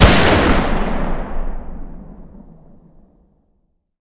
fire-dist-44mag-pistol-ext-06.ogg